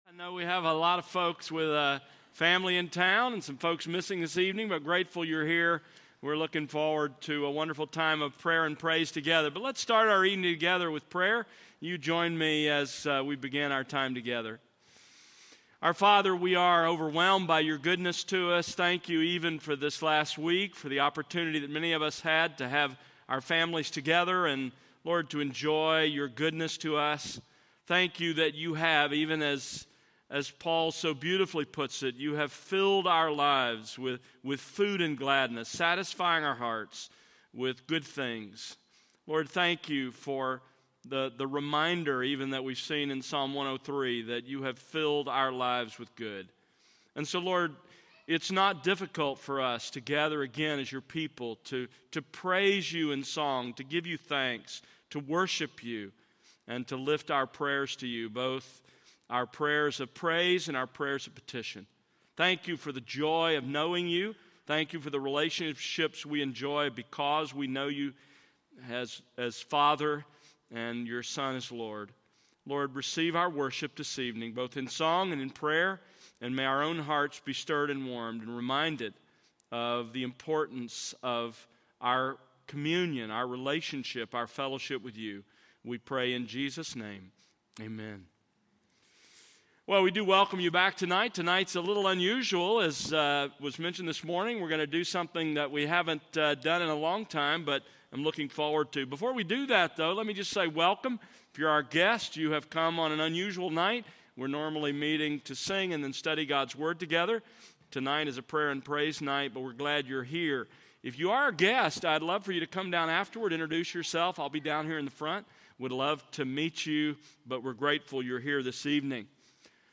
Audio Praise & Prayer Night 2023-11-26 pm Worship Services Praise & Prayer Services Audio Video Series List Next ▶ Current 1.